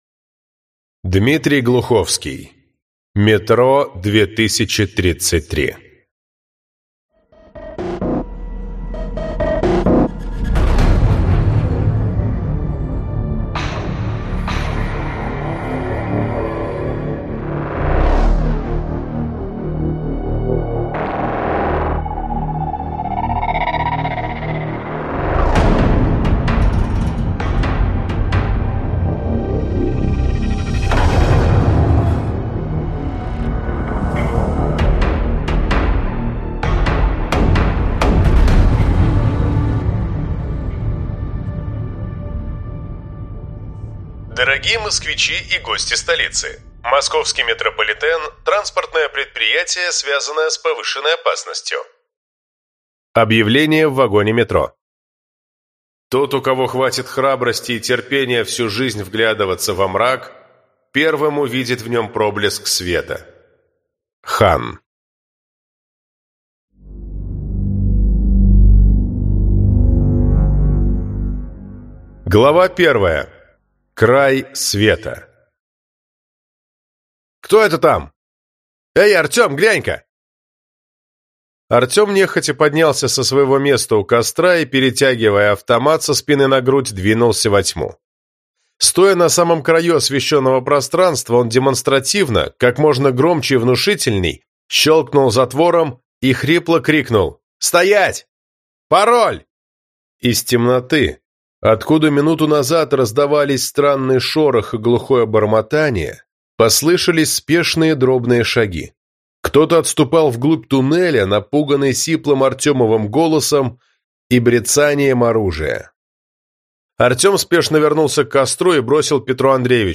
Аудиокнига Метро 2033 - купить, скачать и слушать онлайн | КнигоПоиск